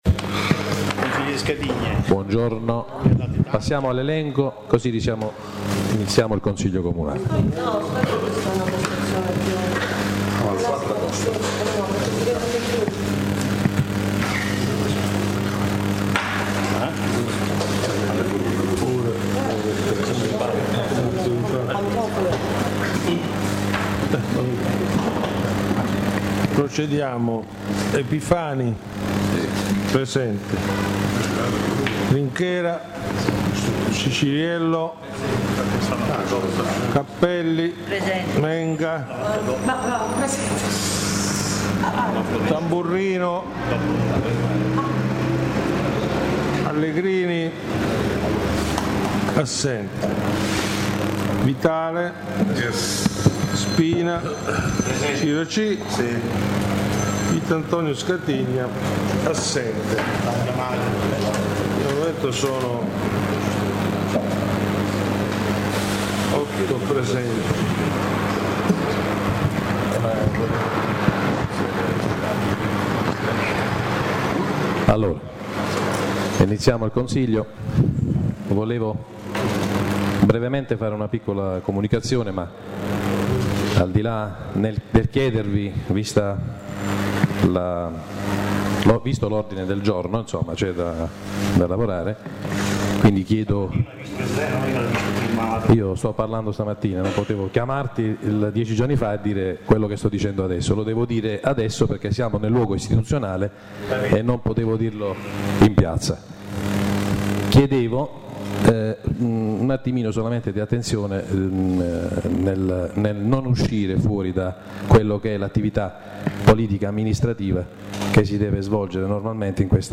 La registrazione audio del Consiglio Comunale di San Michele Salentino del 04/08/2014